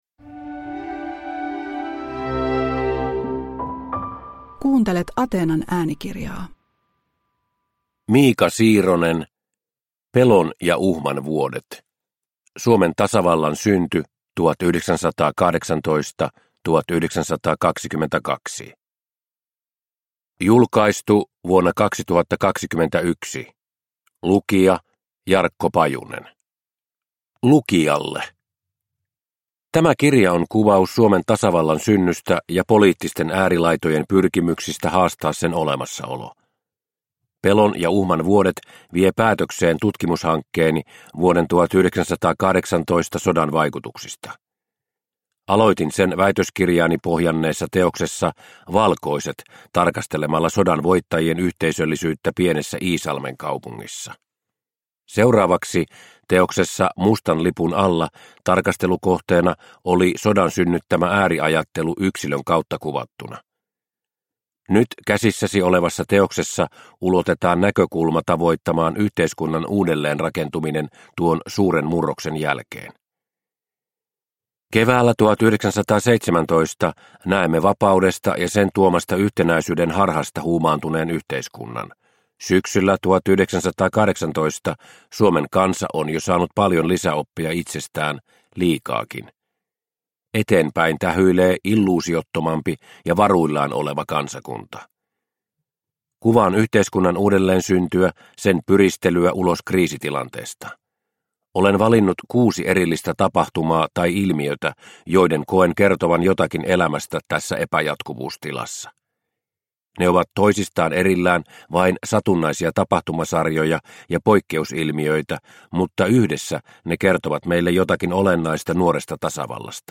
Pelon ja uhman vuodet – Ljudbok – Laddas ner